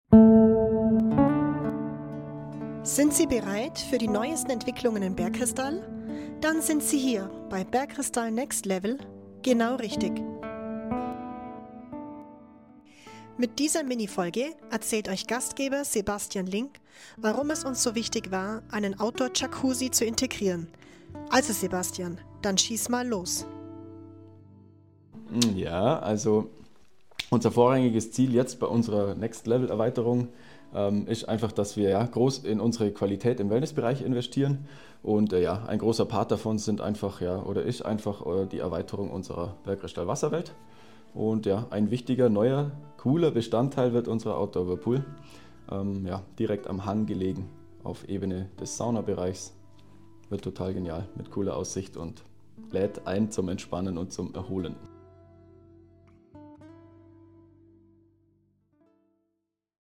Interview: Warum war es uns so wichtig, einen Outdoor-Jacuzzi zu integrieren?